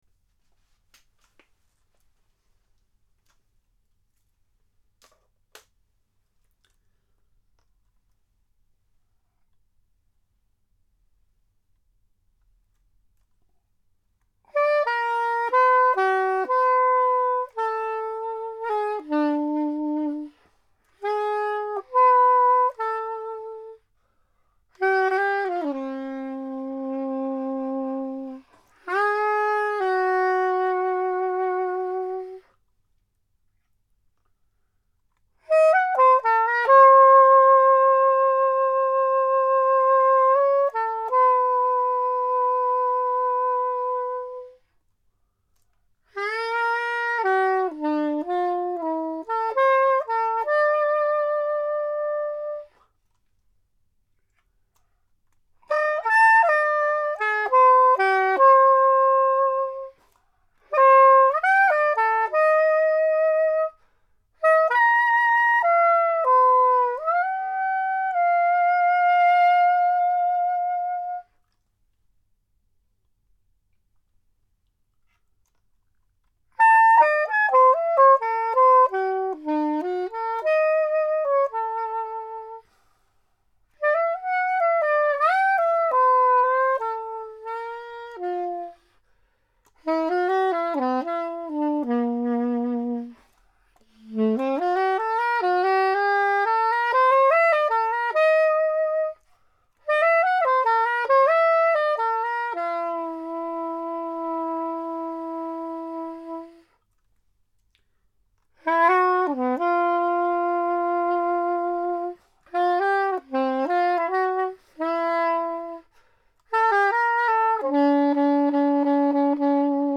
Improvisation (17:57)